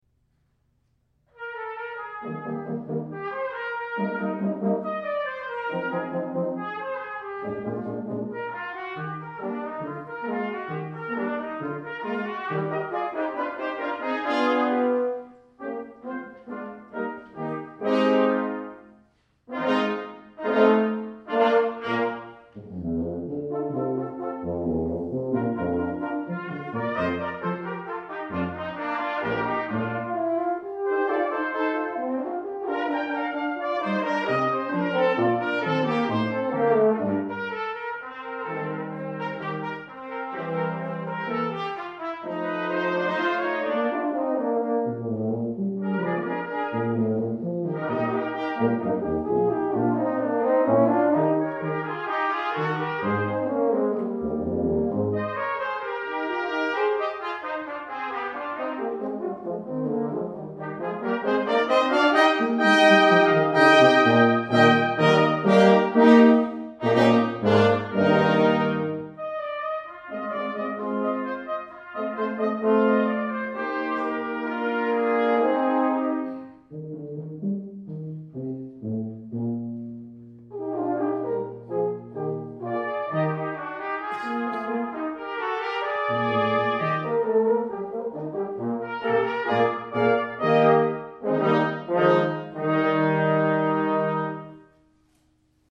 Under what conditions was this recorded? December 2009 Recital: